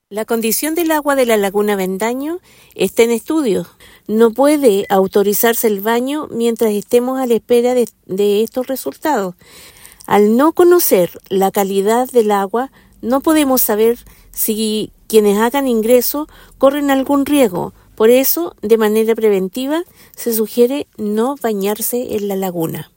Al respecto, la seremi de salud de Ñuble, Ximena Salinas, explicó que no es recomendable y que esta semana se tomaron muestras del agua para análisis biológico y fisicoquímico.